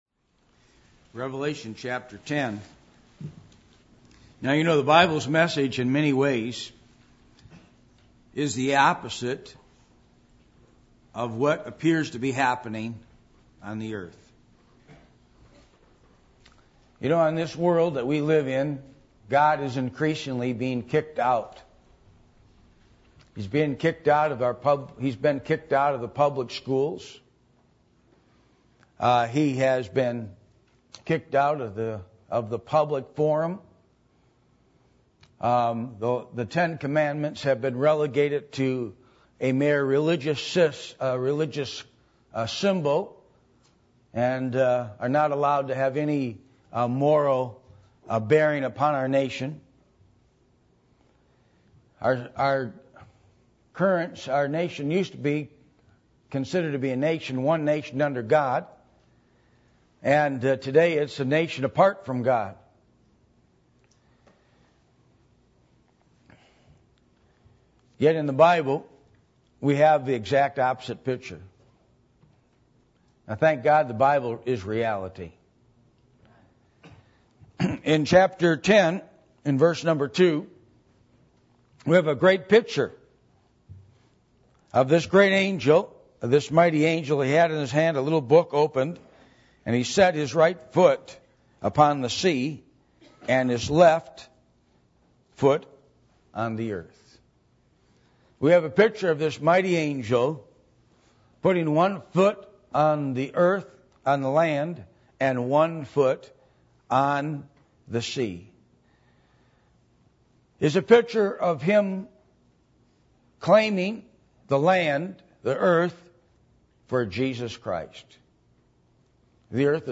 Passage: Revelation 10:1-11 Service Type: Sunday Morning